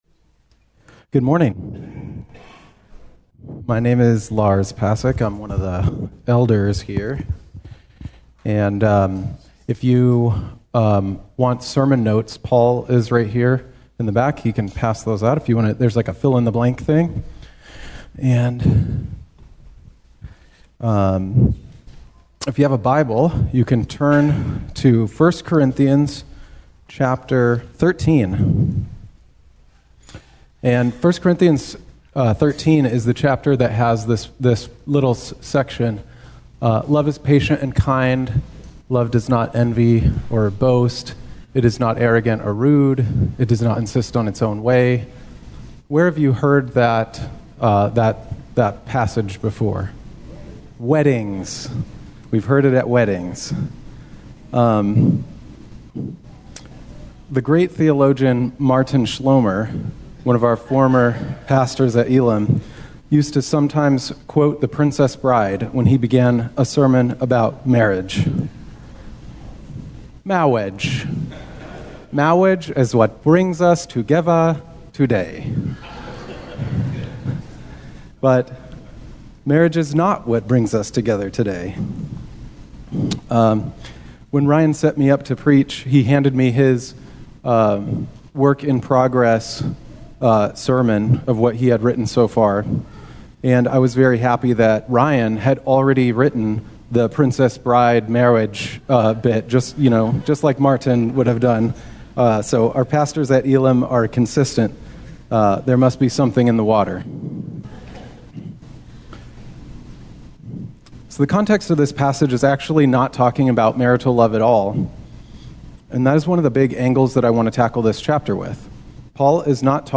Guest speaker elder